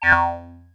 Robot5.wav